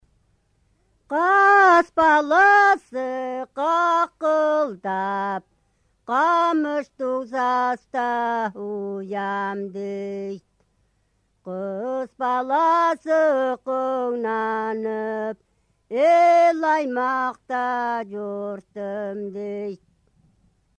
Песенная традиция чалканцев Турочакского района Республики Алтай
Используются преимущественно октавные звукоряды диатонического строения с трехкратным утверждением конечной опоры на нижнем тоне звукоряда. Мелодический контур напевов имеет нисходящую направленность, в третьем периоде часто возникает контрастное мелодическое движение.
Женщины довольно часто поют низкими грудными голосами, тогда как мужчины далеко не всегда используют низкий регистр.
Короткая песня
из села Курмач-Байгол Турочакского р-на Республики Алтай